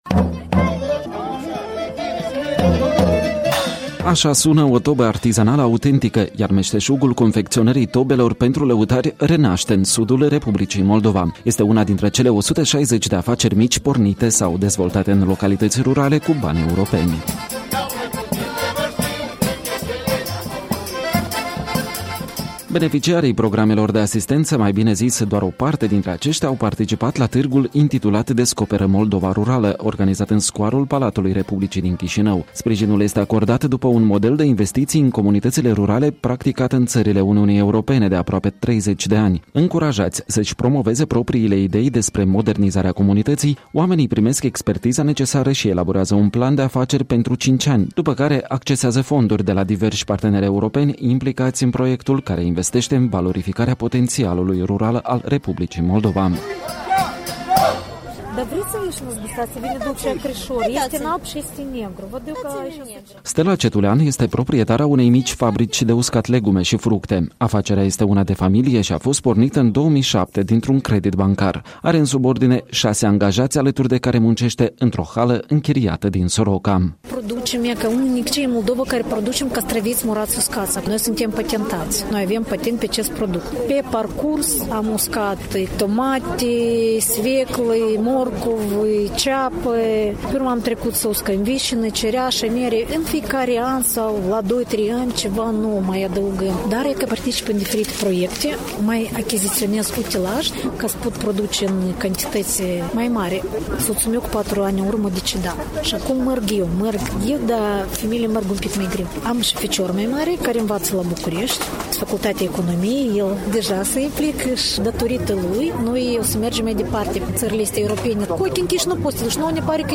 La târgul „Descoperă Moldova rurală”
Reportaj de la o expoziţie a producătorilor locali şi a meşterilor artizani care au beneficiat de finanţări europene.